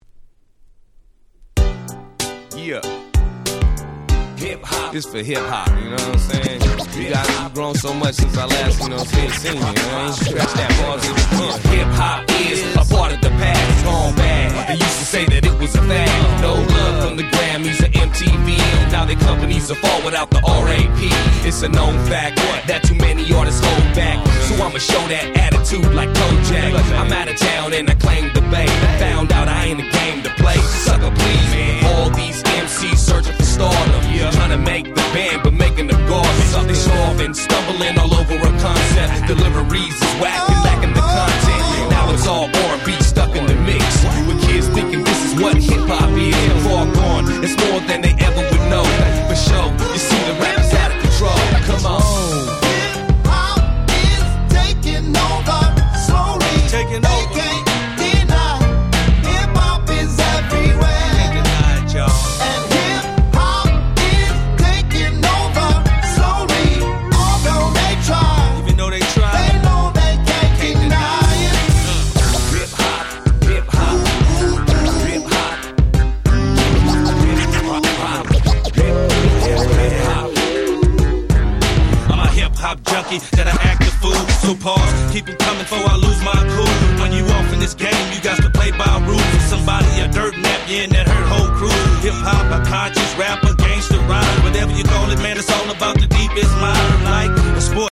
04' Very Nice Chicano Hip Hop !!
キャッチー系 ウエッサイ G-Rap Gangsta Rap